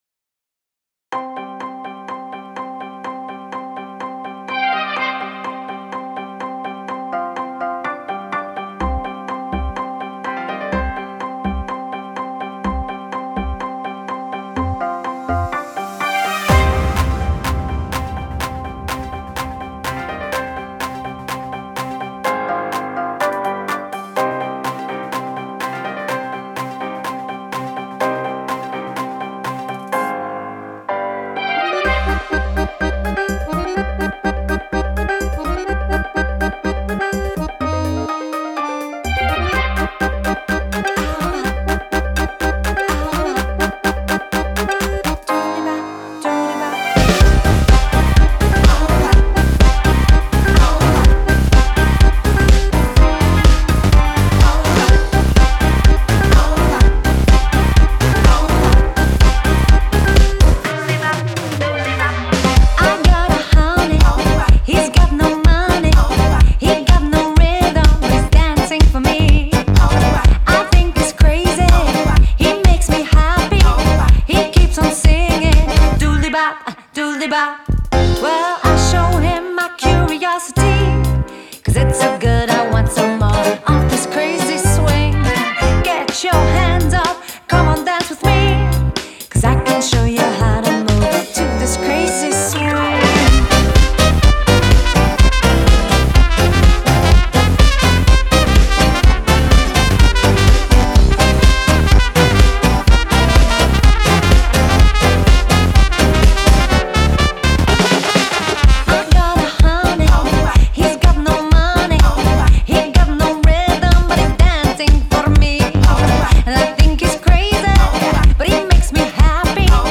Genre: Electronic, Gypsy Jazz, Balkan, Folk, World